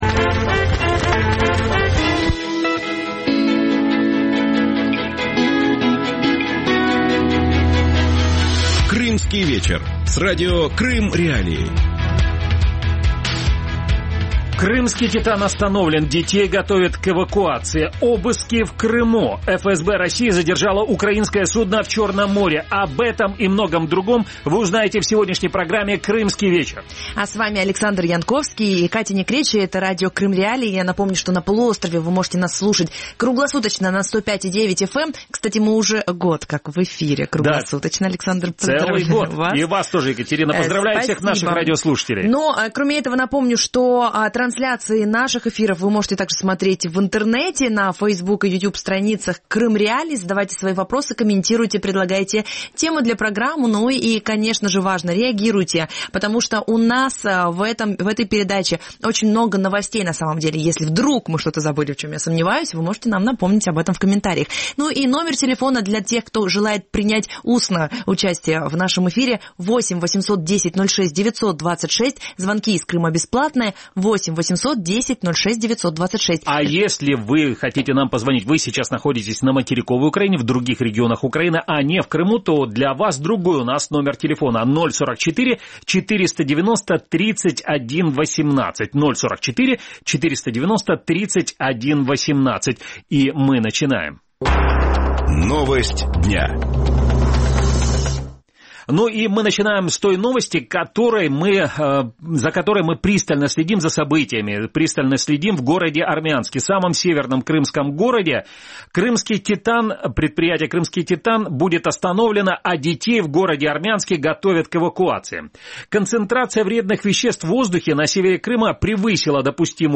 «Крымский вечер» – шоу, которое выходит в эфир на Радио Крым.Реалии в будни с 18:30 до 19:30.